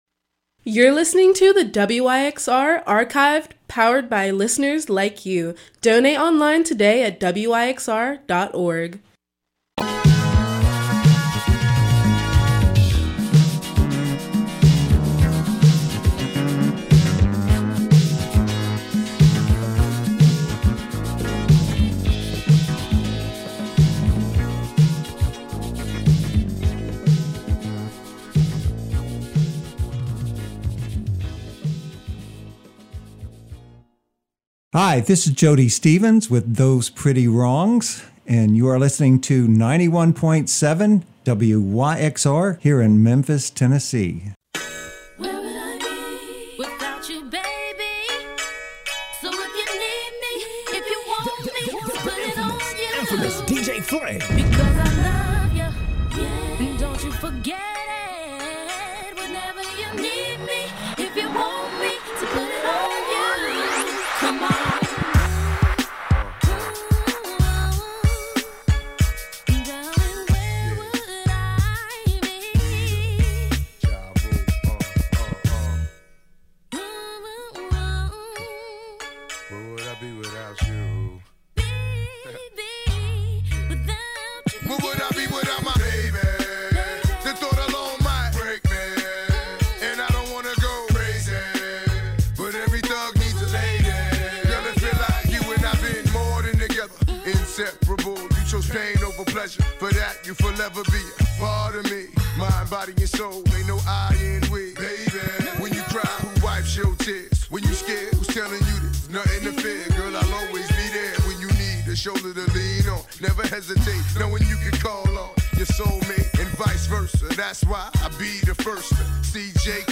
Hip Hop Neo Soul